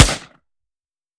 fps_project_1/5.56 M4 Rifle - Gunshot B 004.wav at d65e362539b3b7cbf77d2486b850faf568161f77 - fps_project_1 - Gitea: Git with a cup of tea